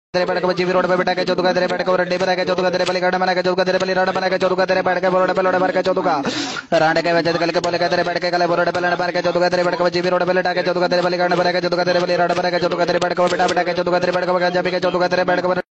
Non stop gali in hindi 2
Listen to Non stop gali in hindi 2, a memes sound button featuring gali, hindi, memesounds on InstantSFX.